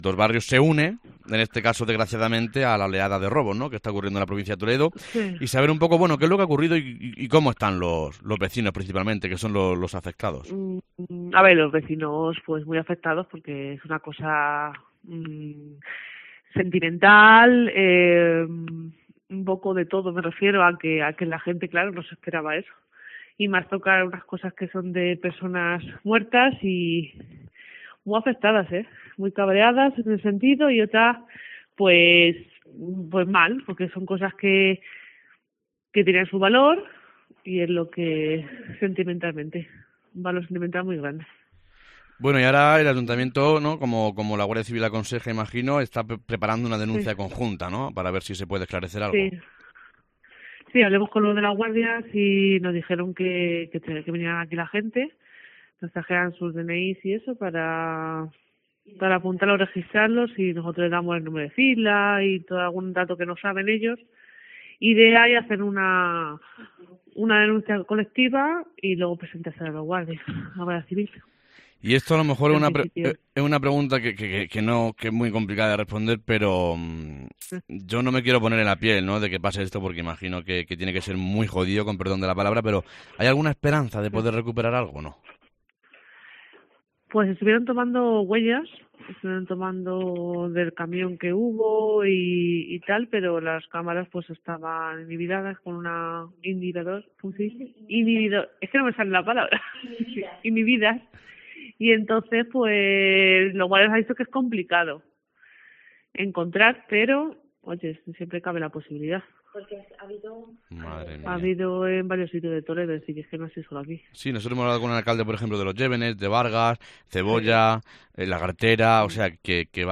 En COPE Toledo hemos hablado con Teresa de Jesús, concejal de Cultura y Festejos, además de afectada, "los vecinos no se esperaba eso. están muy cabreadas y pues mal, porque son cosas que tienen su valor, y es lo que es sentimentalmente, un valor sentimental muy grande".